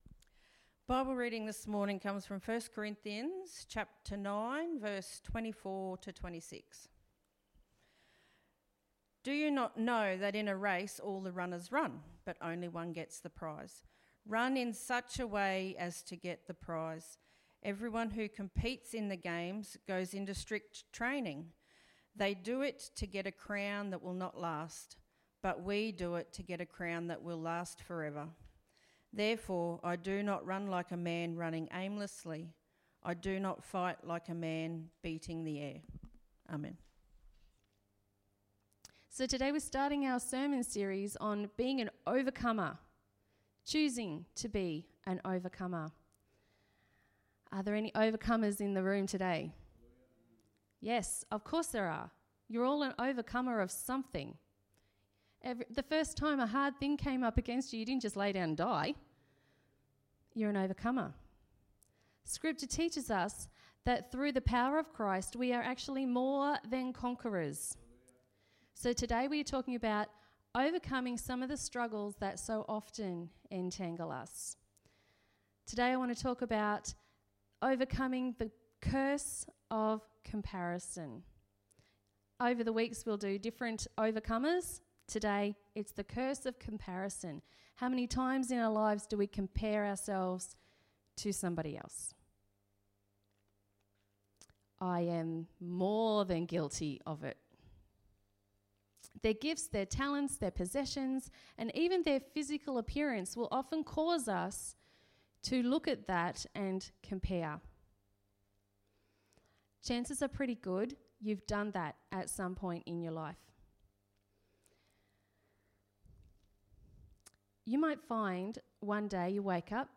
Sermon26.07.2020